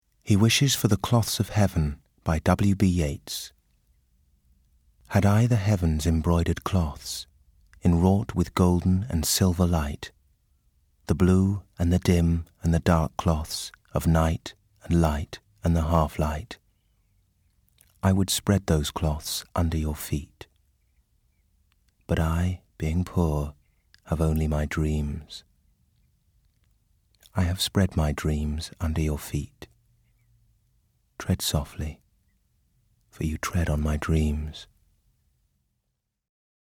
Liverpool, Northern, Yorkshire
Showreel, Slow, Comedy, Smooth, Reassuring